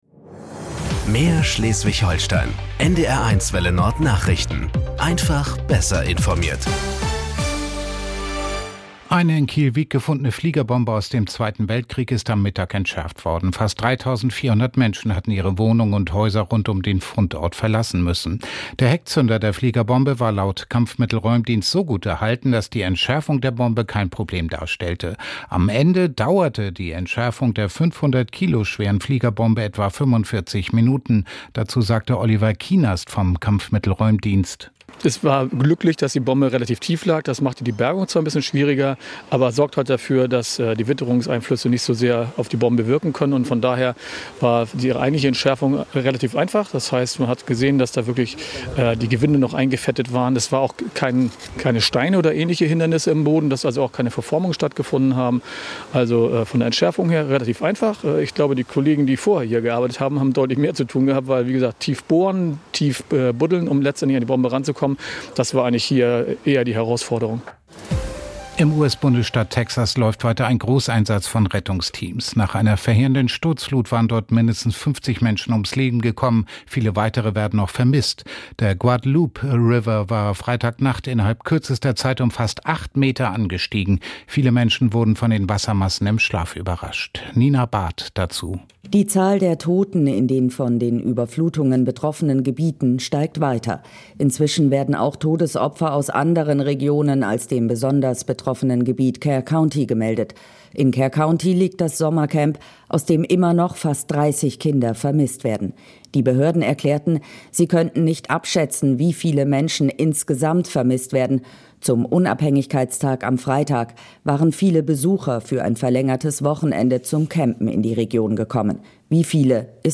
… continue reading 2 епізоди # Tägliche Nachrichten # Nachrichten # NDR 1 Welle Nord